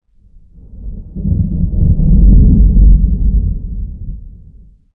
thunder40.ogg